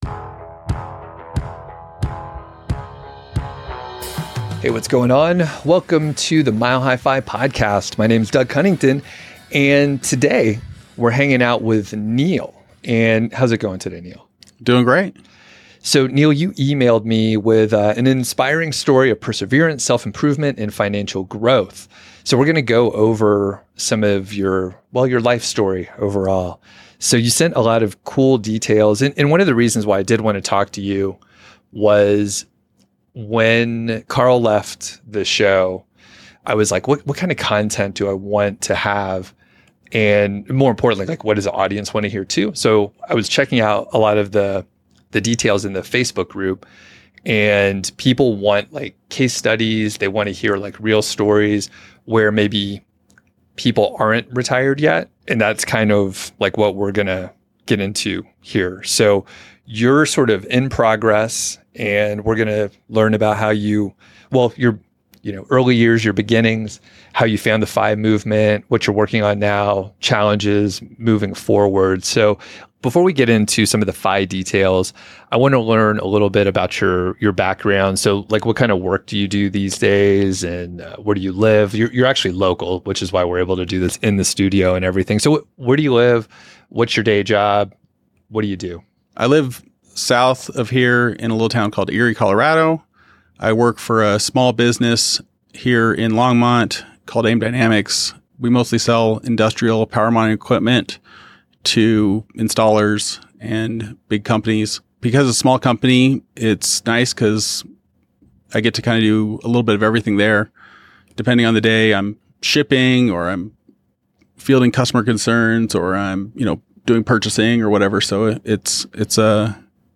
The drama lasts less than twenty minutes, the fill recording is a crime drama, call…